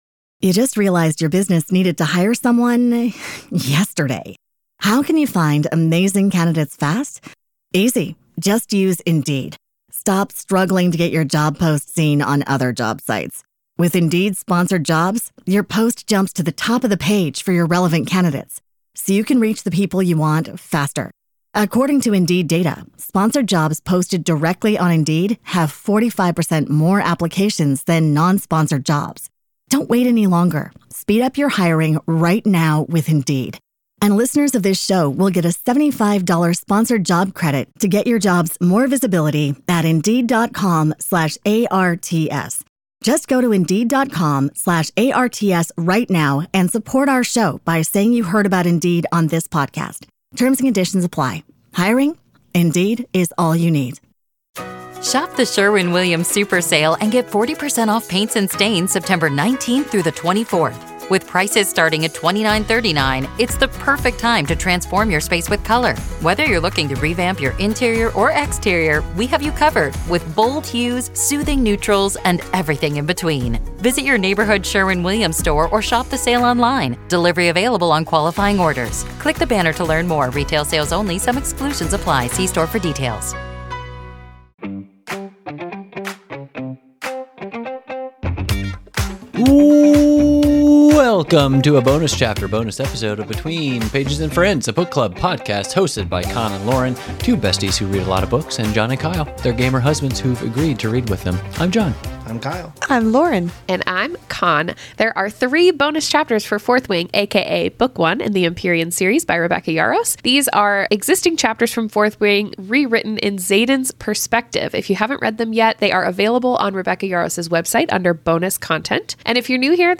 a book club podcast hosted by two couples reading through your favorite fantasy series together.